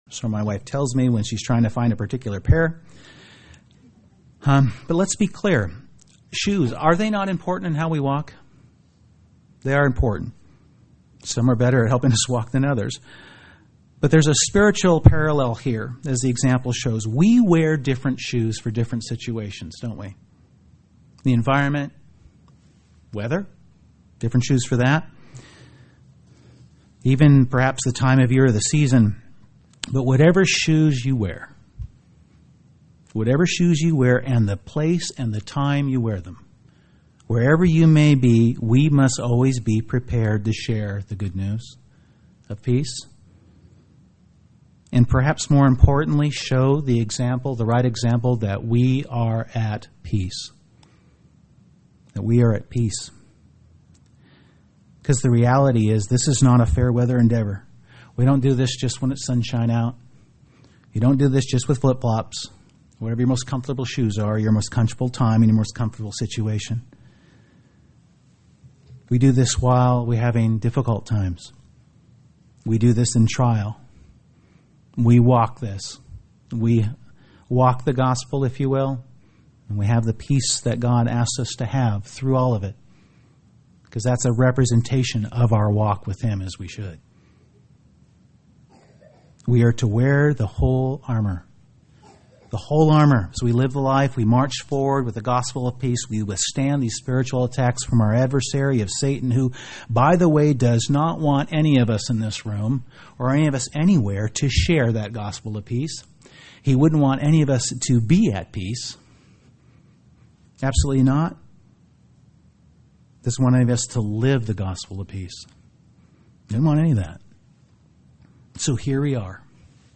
UCG Sermon finding peace Samson and the Philistines Studying the bible?